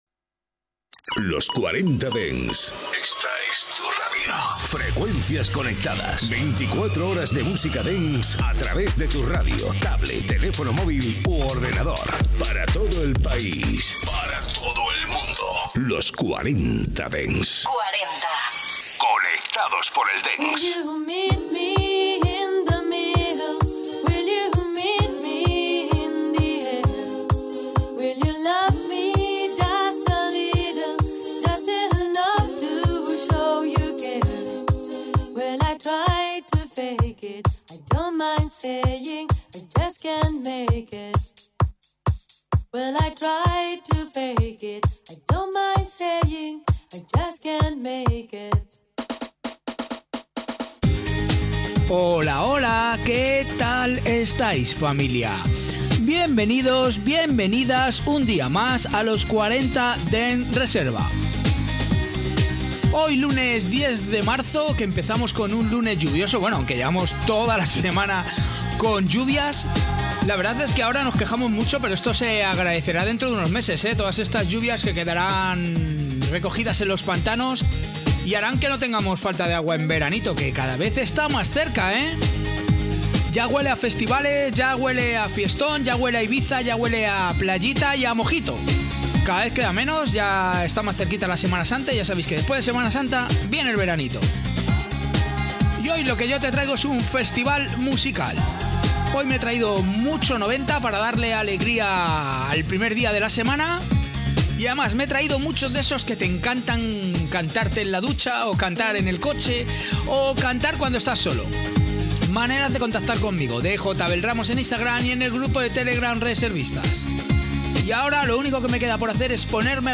Escucha todos los temazos clásicos y míticos de la música dance de las últimas décadas